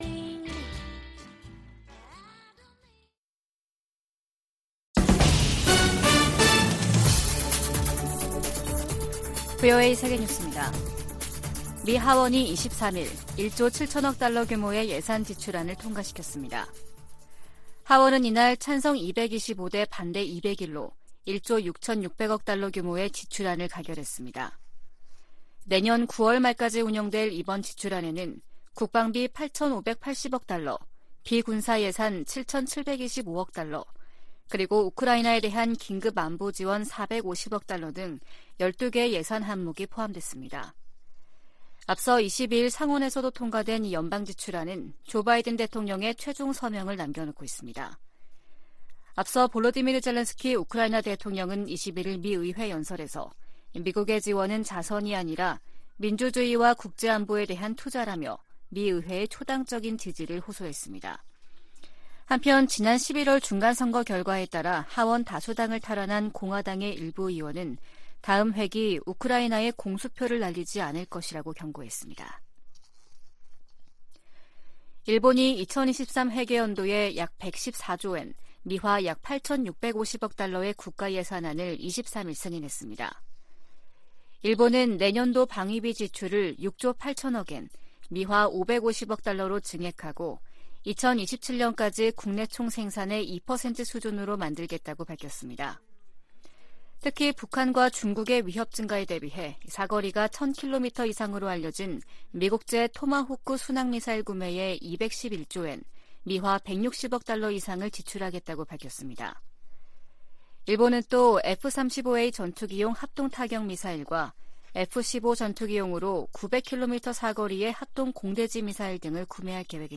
VOA 한국어 아침 뉴스 프로그램 '워싱턴 뉴스 광장' 2022년 12월 24일 방송입니다. 북한이 정찰위성 시험이라며 탄도미사일을 발사한 지 닷새만에 또 다시 탄도미사일을 발사했습니다. 미국 백악관은 러시아의 우크라이나 침공을 지원하는 현지 용병업체에 북한이 로켓과 미사일을 전달했다며 북한 러시아 간 무기 거래 사실을 확인했습니다.